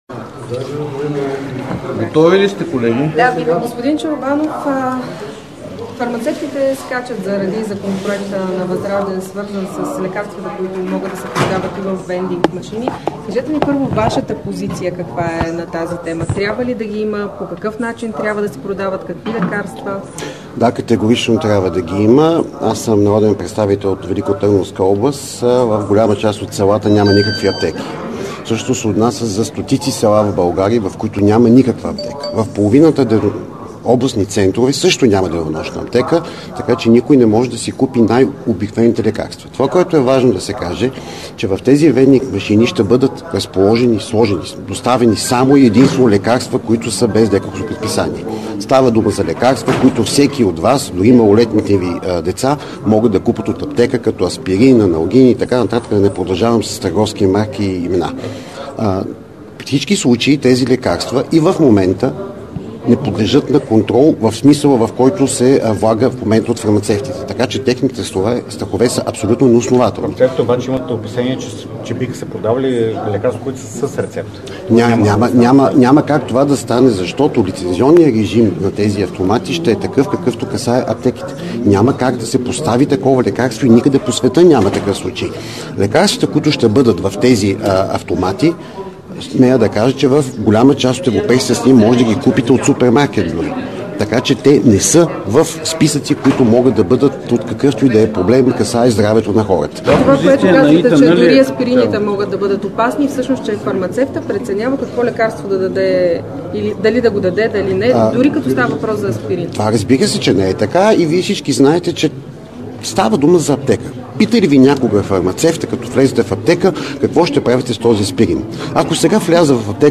10.25 - Брифинг на Искра Михайлова от „Възраждане" за мини Марица Изток.  - директно от мястото на събитието (Народното събрание)
Директно от мястото на събитието